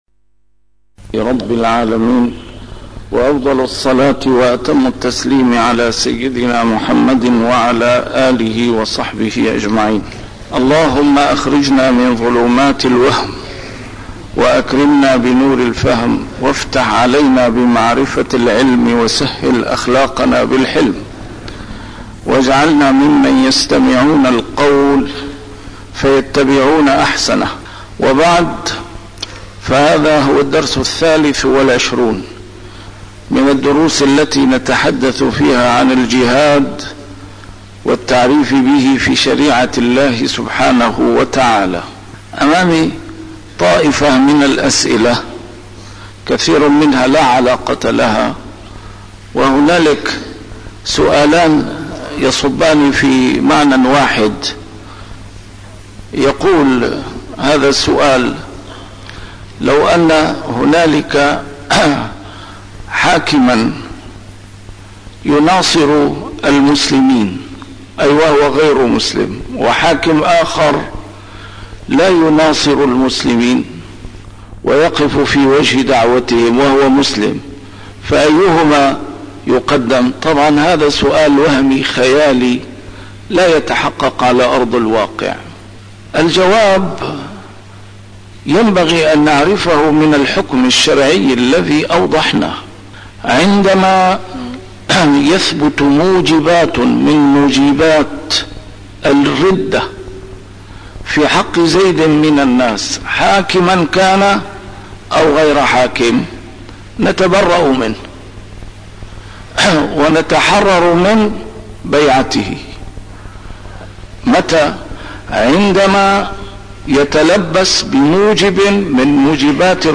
A MARTYR SCHOLAR: IMAM MUHAMMAD SAEED RAMADAN AL-BOUTI - الدروس العلمية - الجهاد في الإسلام - تسجيل قديم - الدرس الثالث والعشرون: سياسة التفكيك التي تتبعها الدول الكبرى في المنطقة